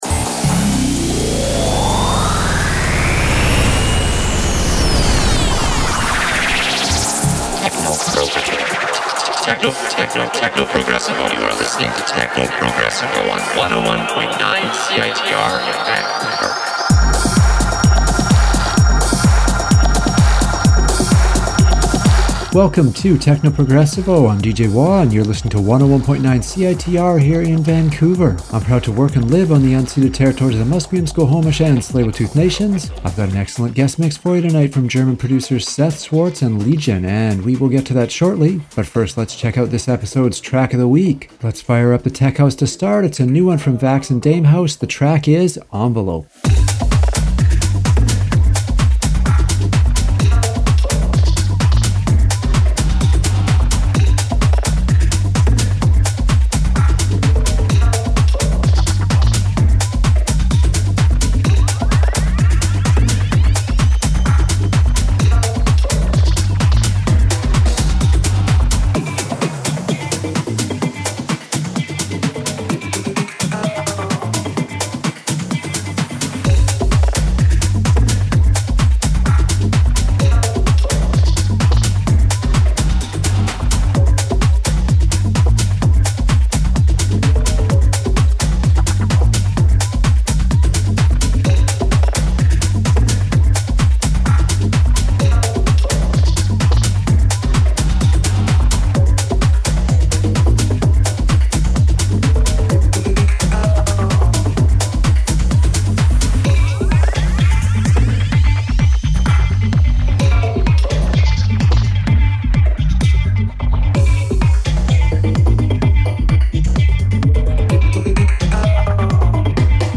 Guest Mix